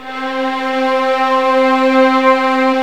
Index of /90_sSampleCDs/Roland - String Master Series/STR_Vlns 2-5/STR_Vls2 Arco NS